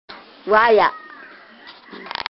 山口方言ライブラリ